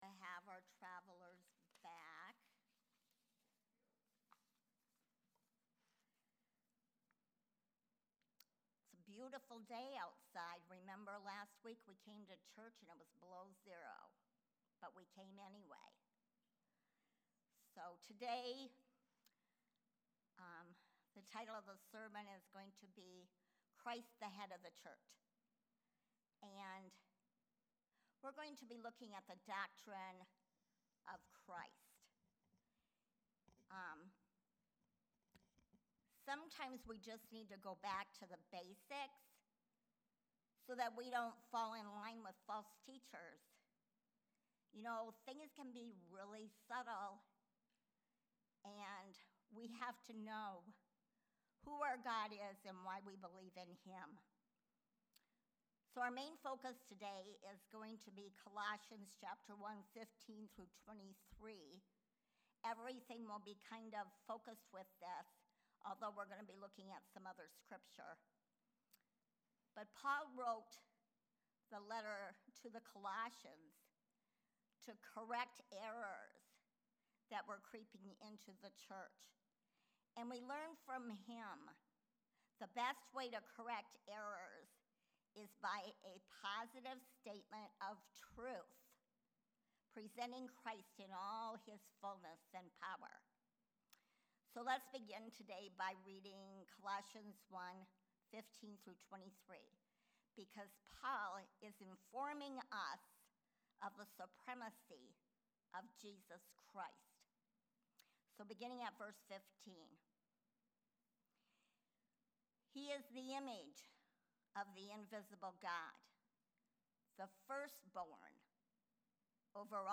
Sermons | Friendship Assembly of God